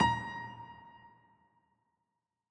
files AfterStory/Doki Doki Literature Club/game/mod_assets/sounds/piano_keys
A5sh.ogg